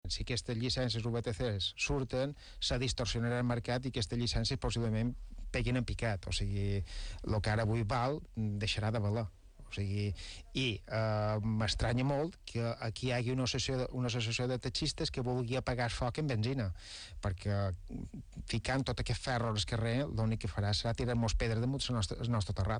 Són declaracions a l’informatiu vespre d’IB3 Ràdio.